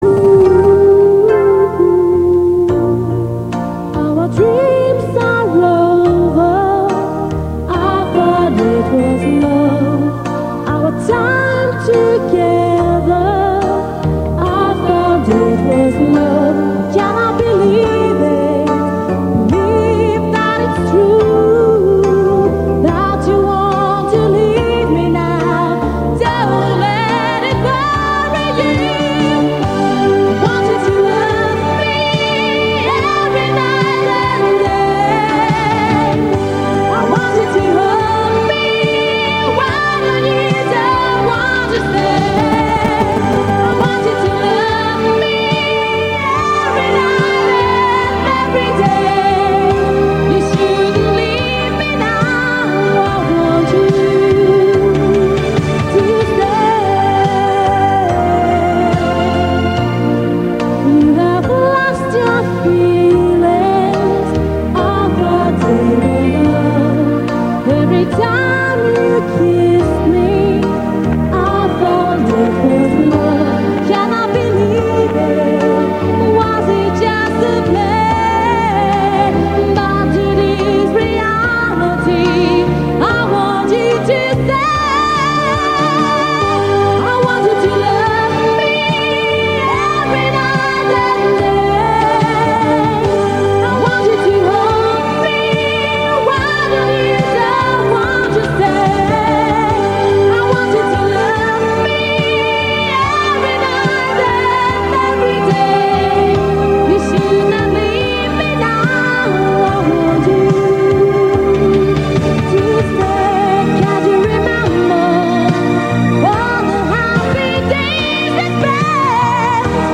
Já ten text taky zkoušel, ale je to tak klasická "romantická" odrhovačka plná oblíbených rýmovacích frází, že části textu seděly na spoustu jiných podobných "romantických" písniček.
Každopádně to podle typické GSM pípání mi k té době nesedí.
Nemůže to být AI pokus o retro disko?
Ne, určitě je to staré, je to nahrávka z rakouského rádia na audio kazetu (i s mobilem skenujícím vysílač, toho jsem tenkrát nevšimnul).